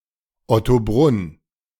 Ottobrunn (German: [ɔtoˈbʁʊn]
De-Ottobrunn.ogg.mp3